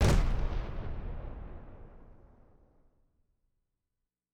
Tm8_HatxPerc17.wav